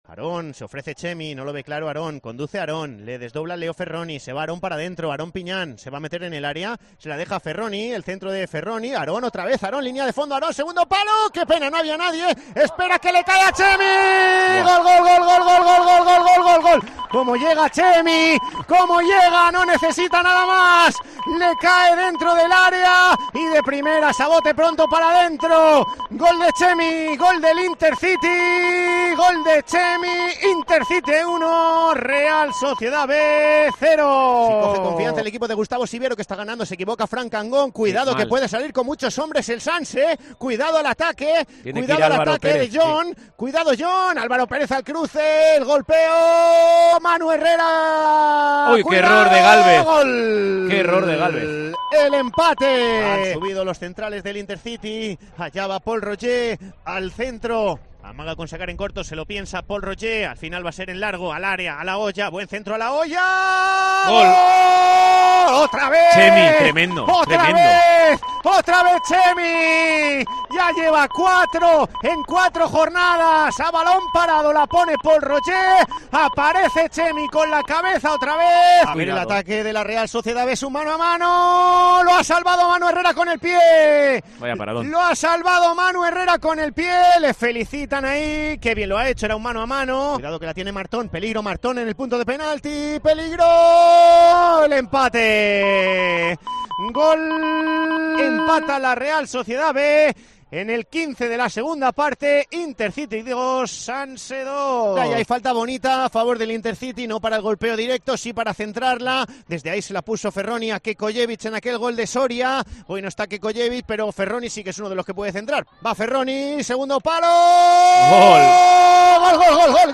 Resumen de sonidos del Intercity 5-2 Real Sociedad B